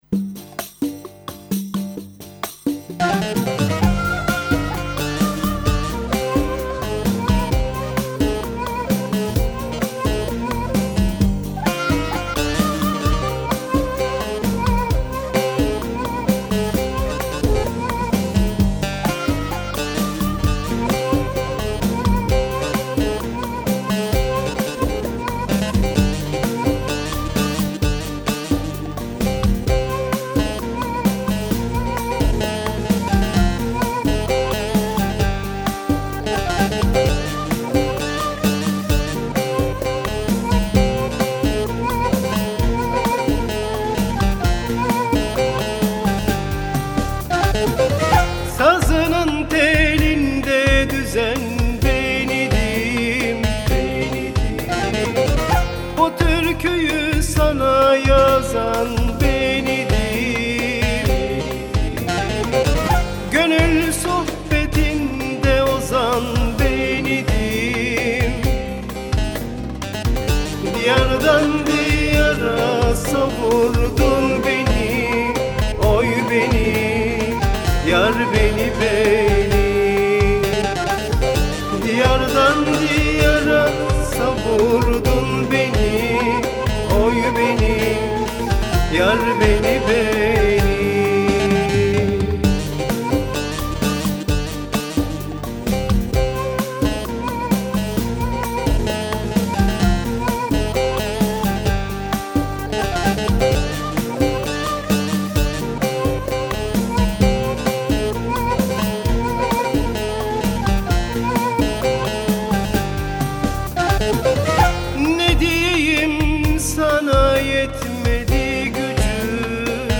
Halk Müziği